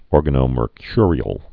(ôr-gănō-mər-kyrē-əl)